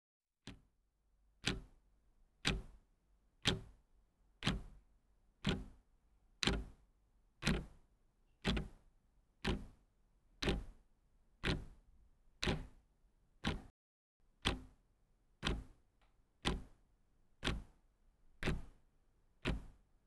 Clock Tick Sound
household
Clock Tick